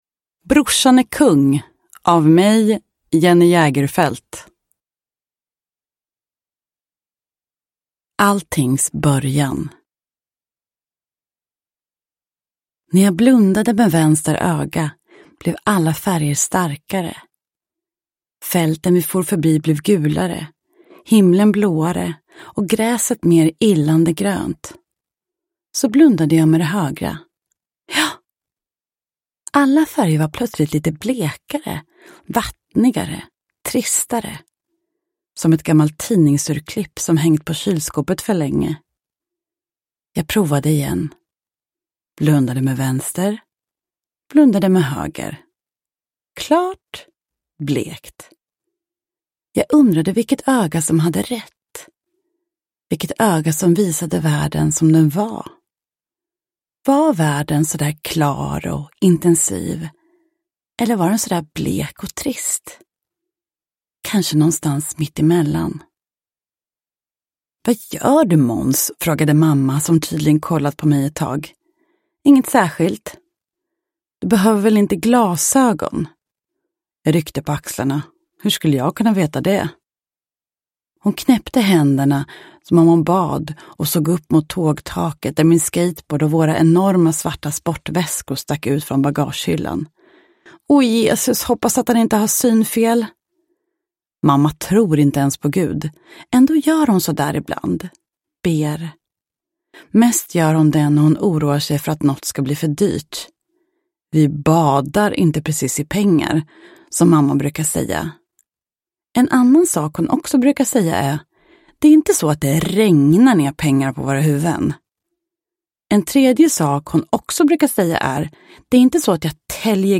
Uppläsare: Jenny Jägerfeld